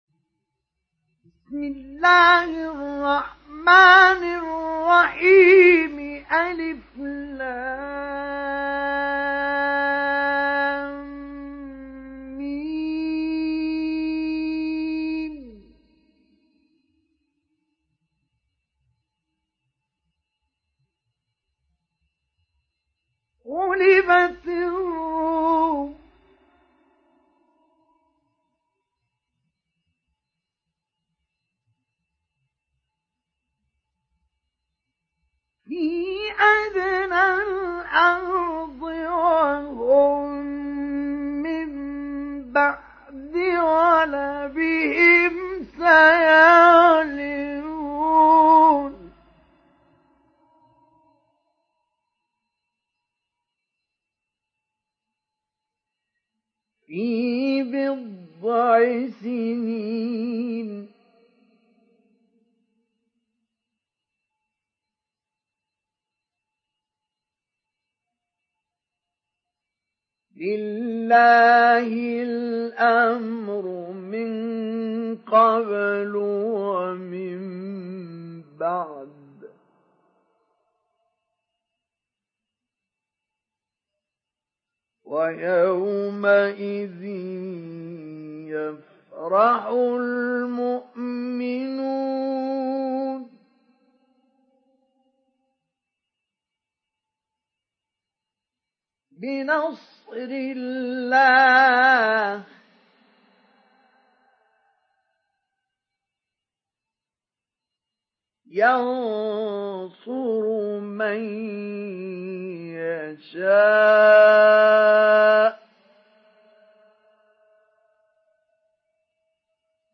Surat Ar Rum Download mp3 Mustafa Ismail Mujawwad Riwayat Hafs dari Asim, Download Quran dan mendengarkan mp3 tautan langsung penuh
Download Surat Ar Rum Mustafa Ismail Mujawwad